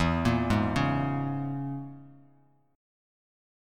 E6b5 Chord